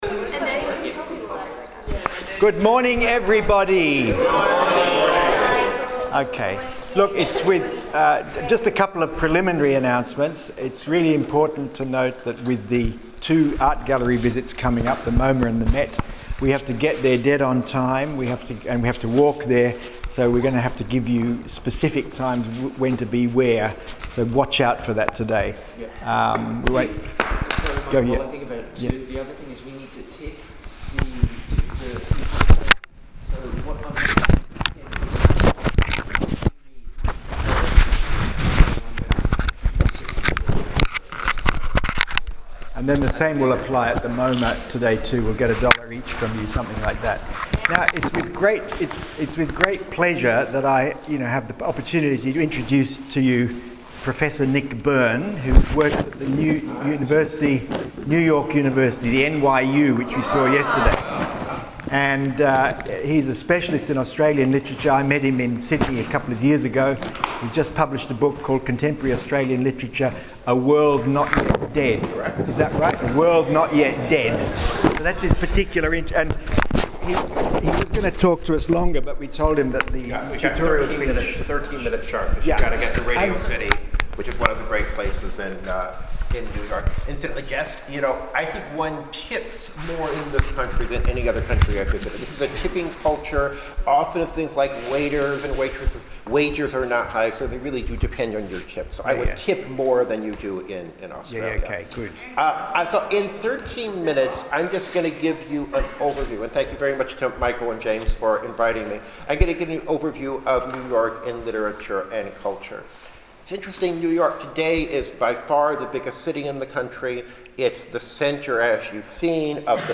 Here are a few moments of his gracious, informative talk: